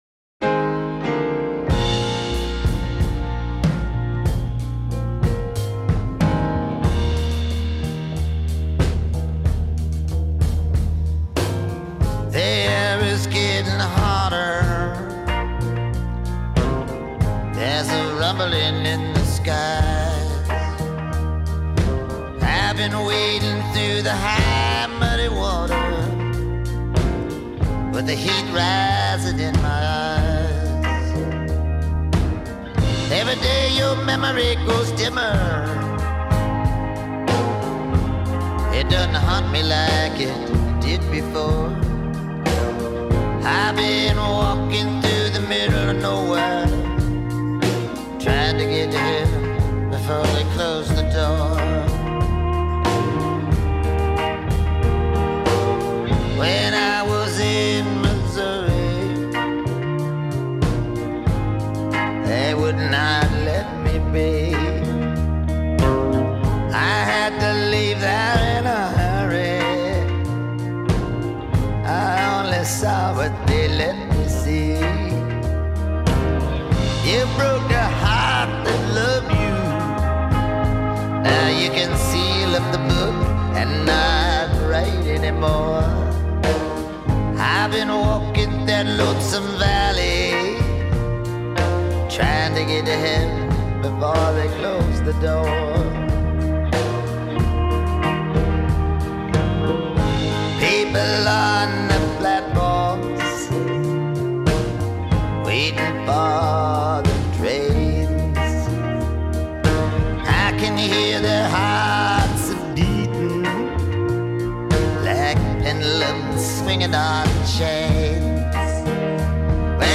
slow blues ballad